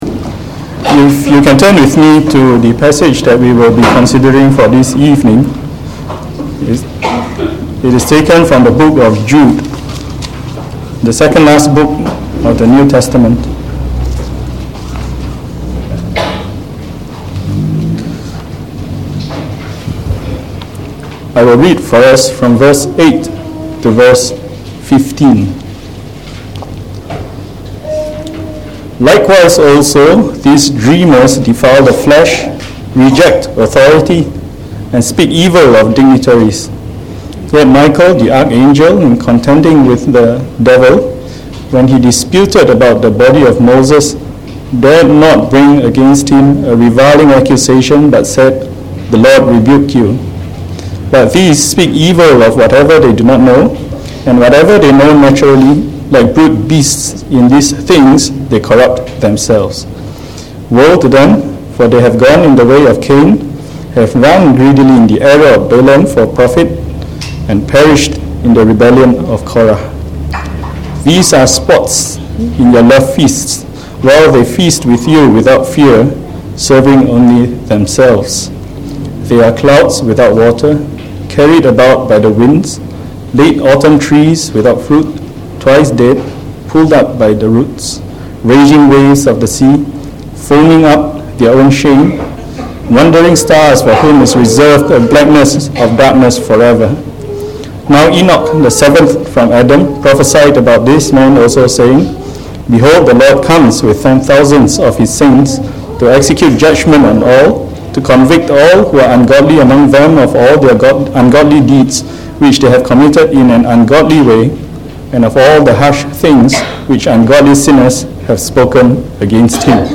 From our series on the Epistle Of Jude delivered in the Evening Service